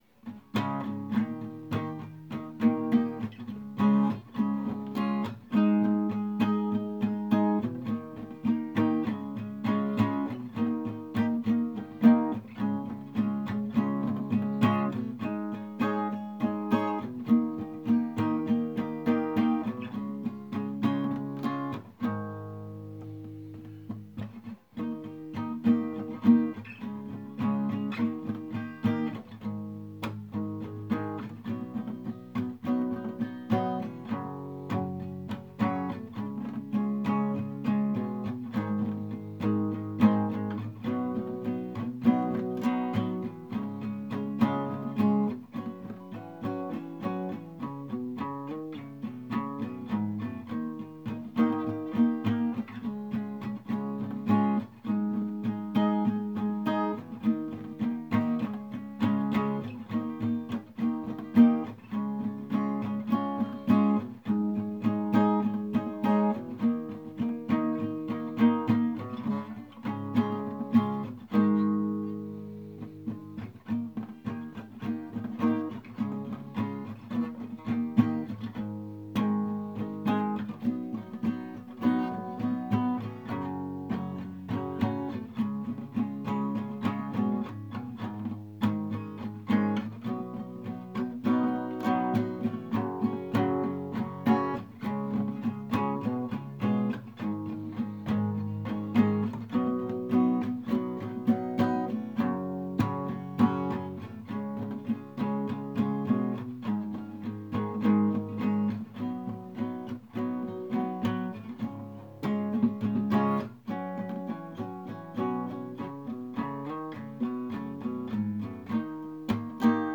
music_smorgasbord_semiconductor_acoustic.m4a